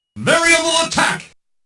Unused voices
There's also an unused voice clip for the